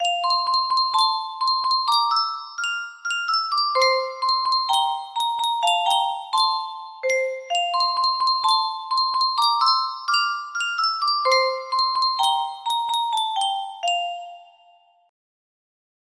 Yunsheng Music Box - Jamaica Farewell 4262 music box melody
Full range 60